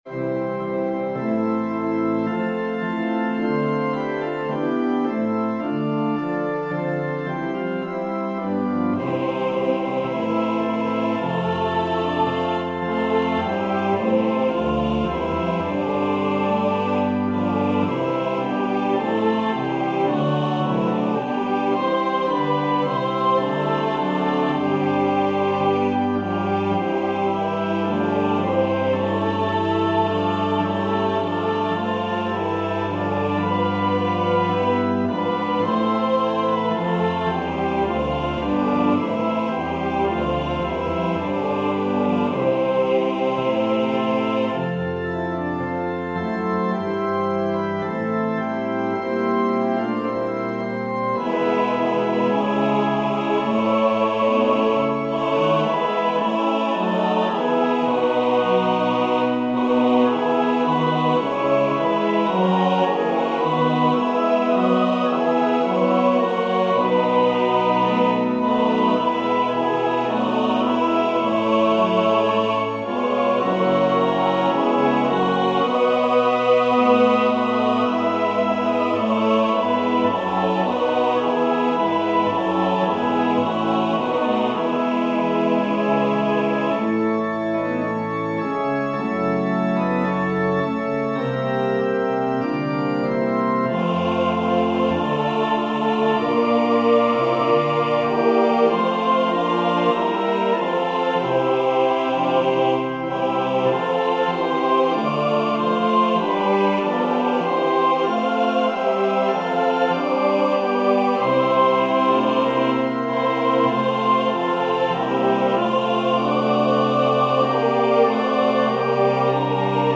Voicing/Instrumentation: SATB , Organ/Organ Accompaniment